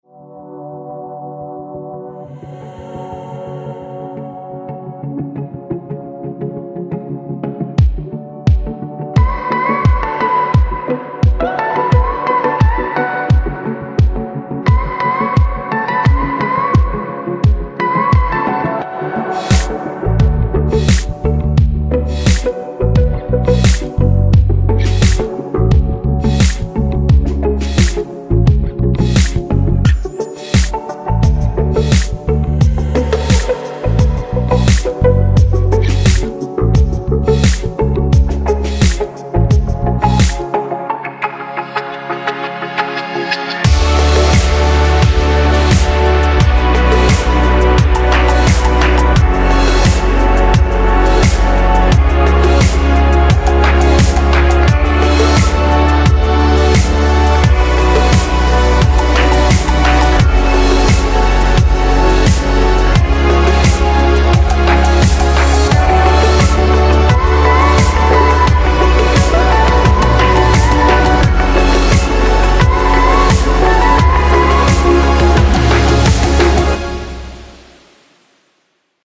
• Качество: 192, Stereo
ритмичные
dance
Electronic
без слов
из рекламы
красивая музыка